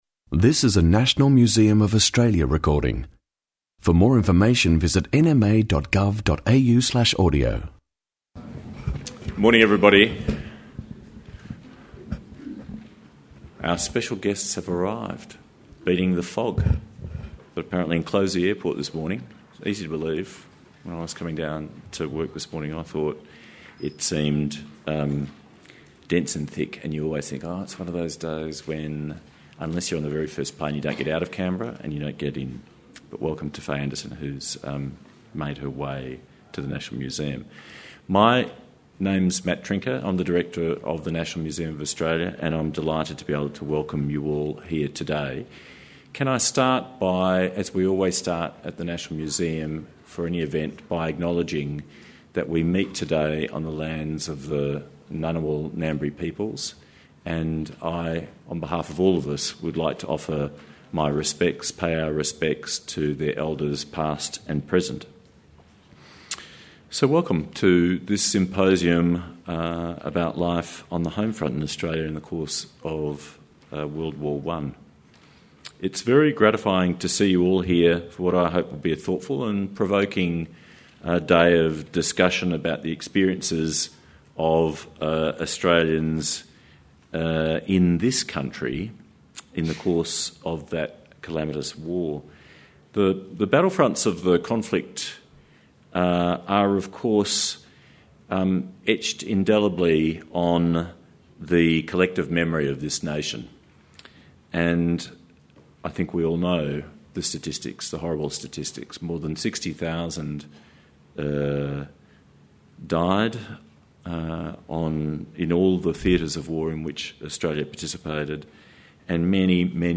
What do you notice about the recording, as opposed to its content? Life on the Home Front symposium 07 Aug 2015